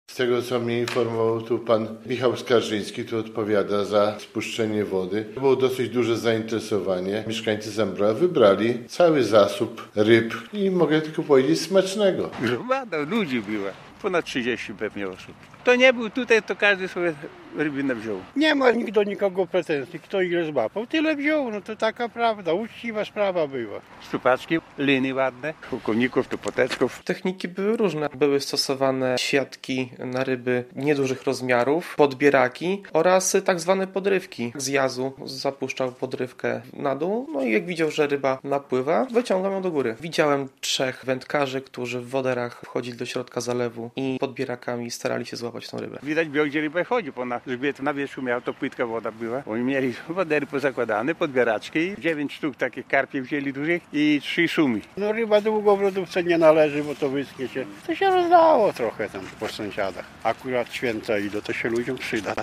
Staw w Zambrowie osuszony, ryby wyłowili mieszkańcy - relacja